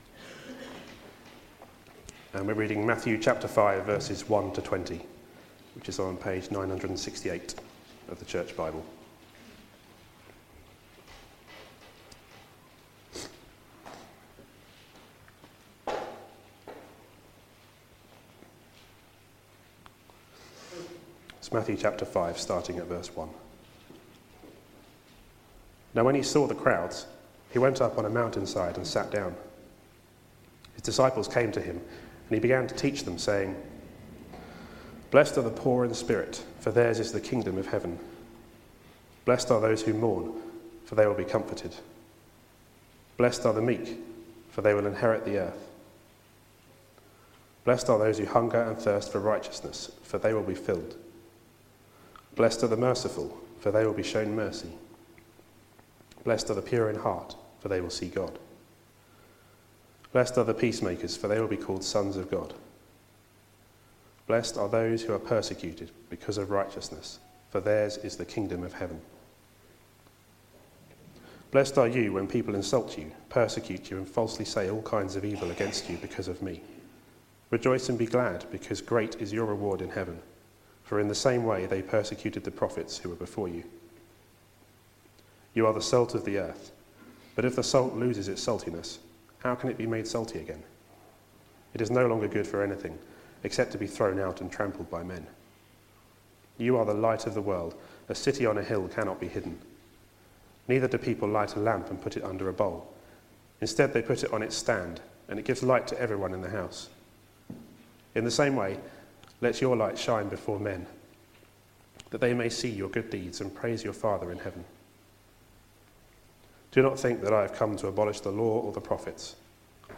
Media for Sunday Service